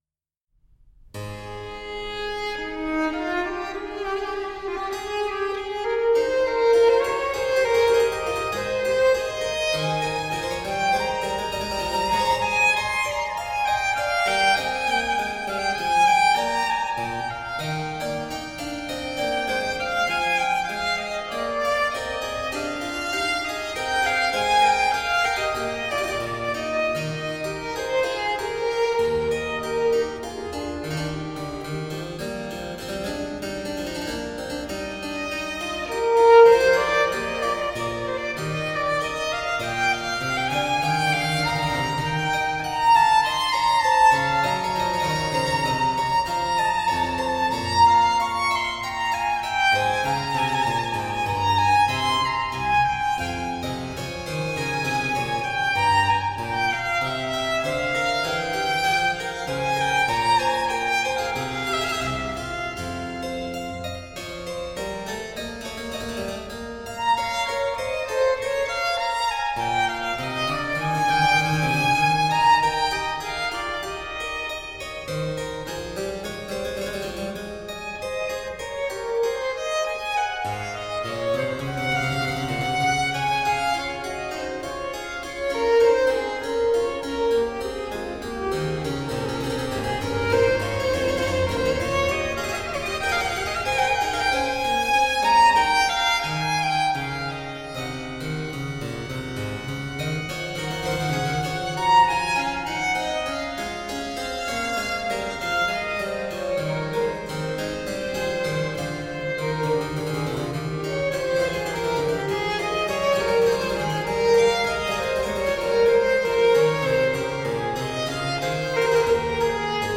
Lilting renaissance & baroque vocal interpretations .
Classical, Chamber Music, Baroque, Instrumental, Cello